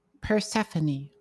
In ancient Greek mythology and religion, Persephone (/pərˈsɛfən/